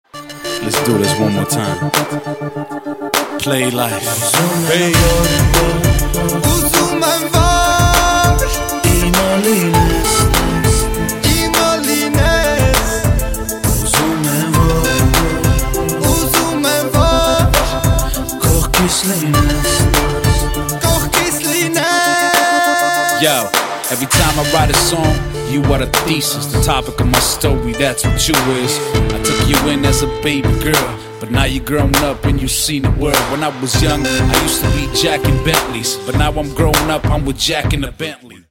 дуэт
поп , рэп